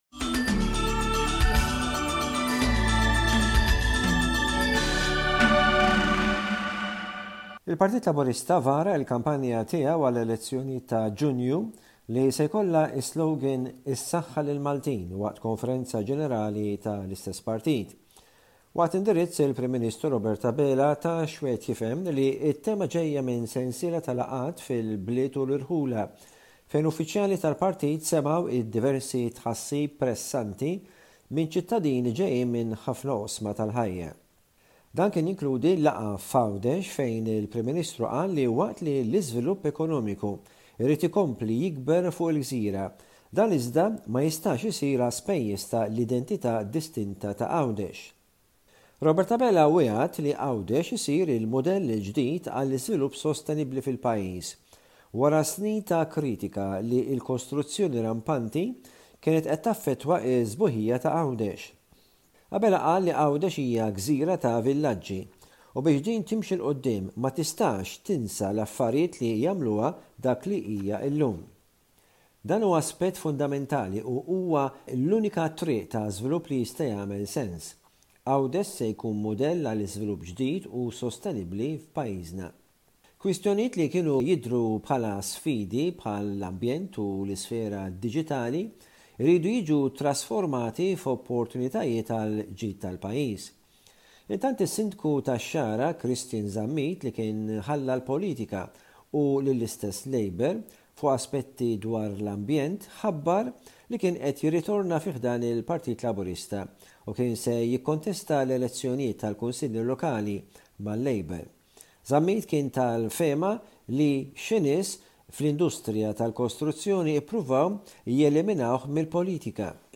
News report from Malta by SBS Radio correspondent